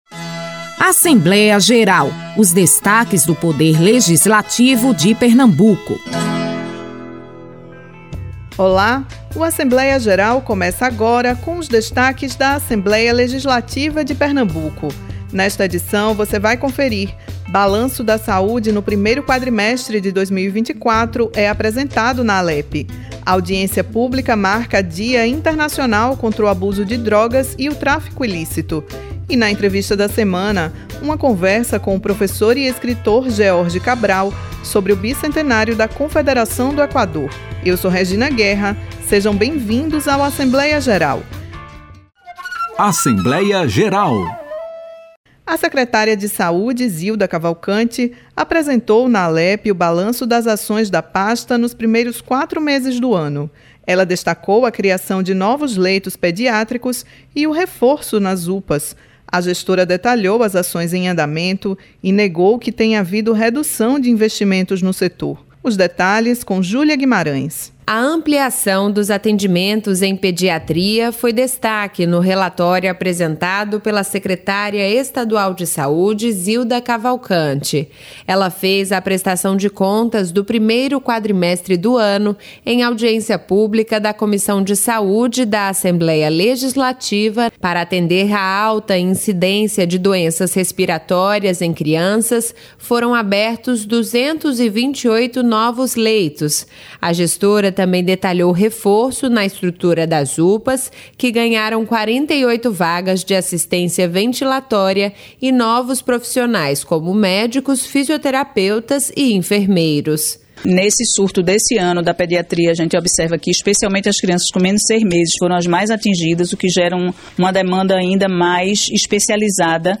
E, na entrevista da semana, a segunda parte da conversa